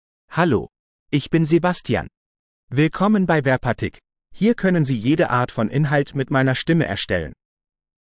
MaleGerman (Germany)
SebastianMale German AI voice
Sebastian is a male AI voice for German (Germany).
Voice sample
Sebastian delivers clear pronunciation with authentic Germany German intonation, making your content sound professionally produced.